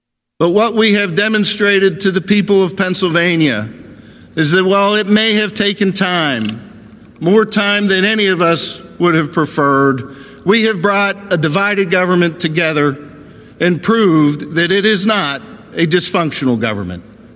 On the floor of the Senate, Pittman said that the budget took longer to pass than anyone would have liked, but it showed that a divided government can work.